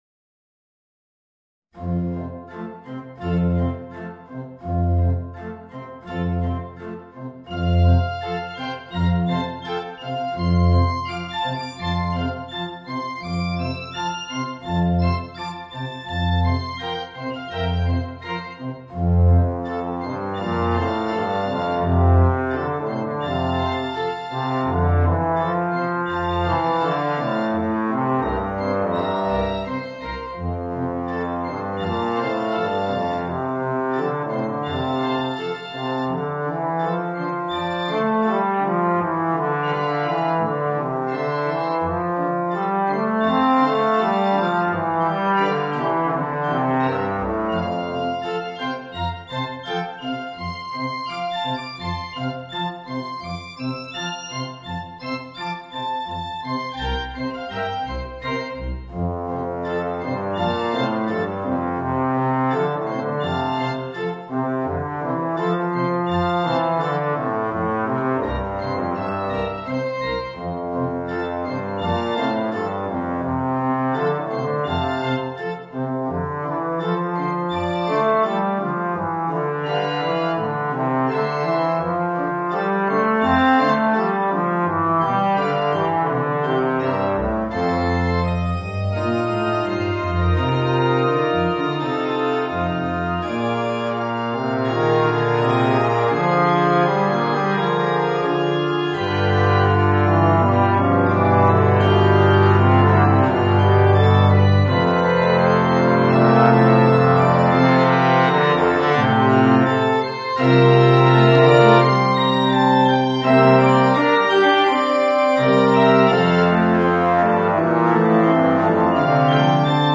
Voicing: Bass Trombone and Organ